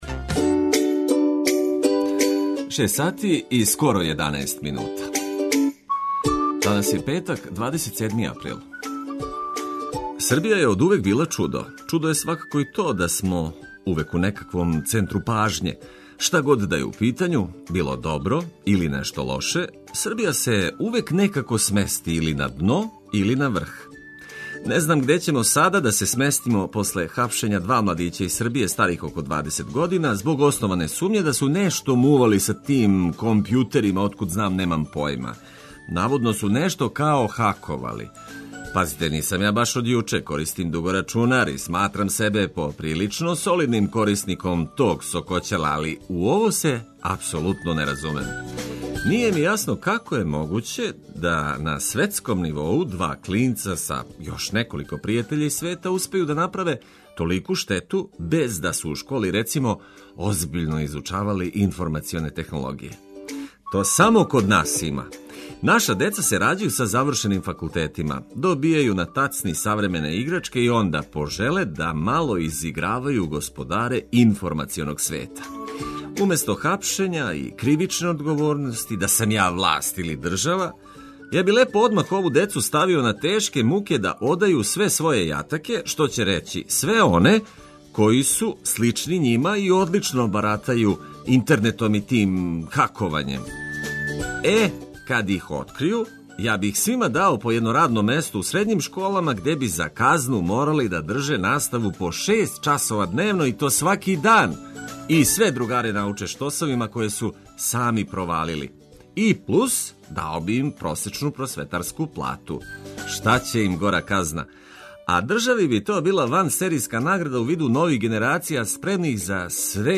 Важне информације уз најбољу музику и добро расположену екипу.